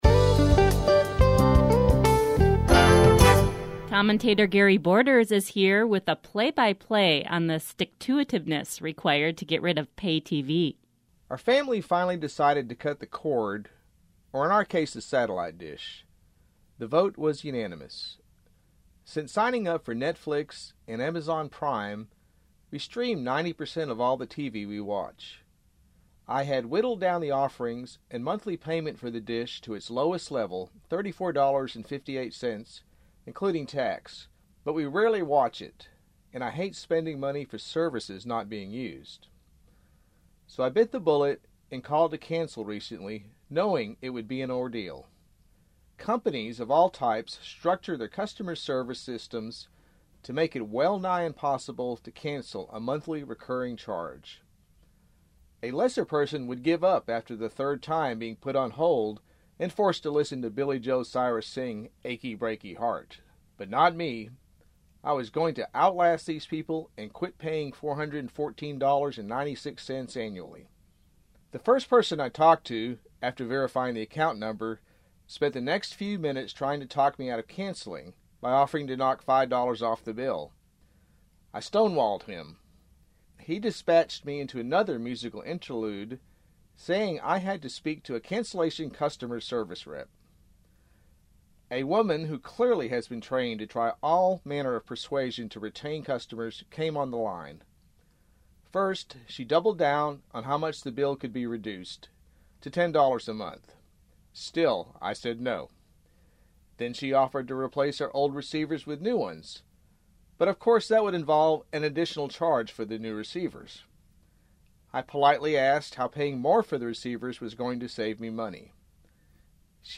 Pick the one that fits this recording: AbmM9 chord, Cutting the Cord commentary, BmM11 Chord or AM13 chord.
Cutting the Cord commentary